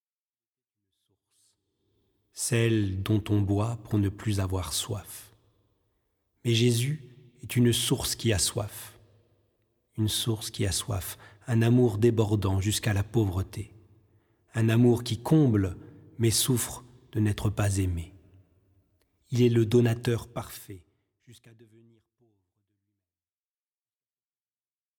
Largo (1.29 EUR)